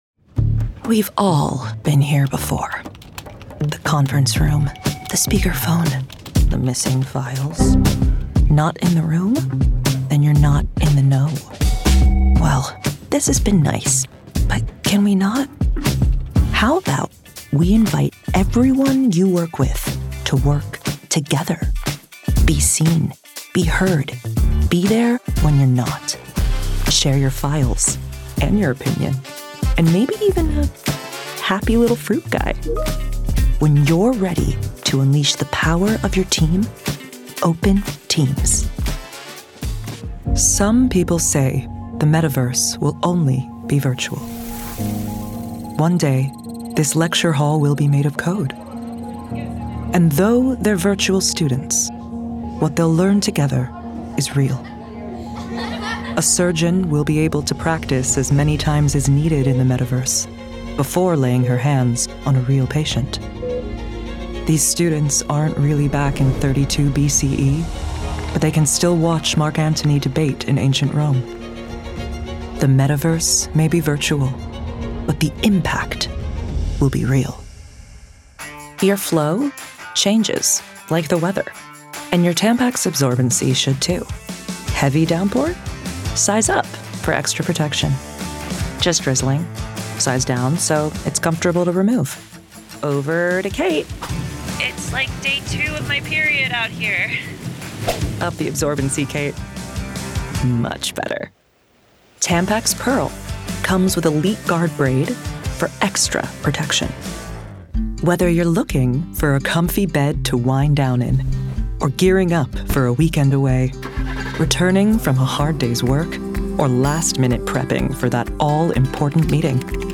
Commercial Showreel
Female
American Standard
Confident
Cool
Friendly
Smooth
Husky
Gravitas